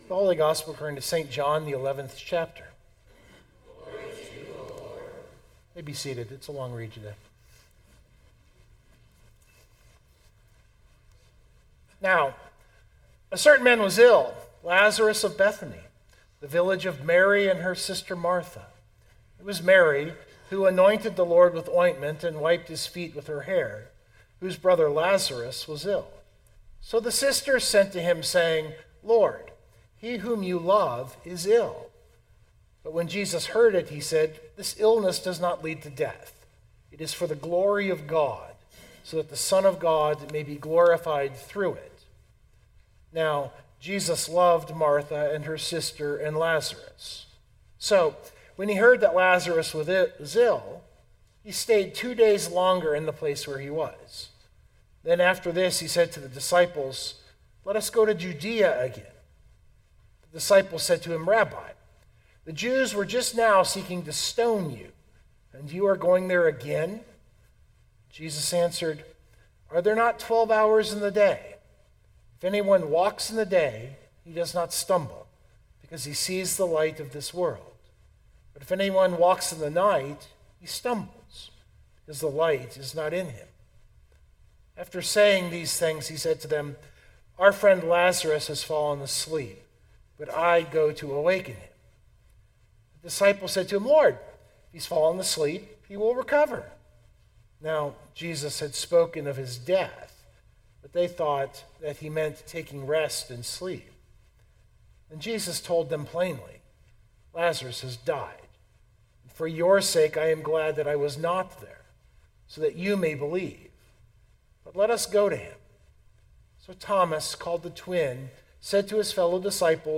032226 Sermon Download Biblical Text: John 11:1-48 The introduction on a non-essential point is too long.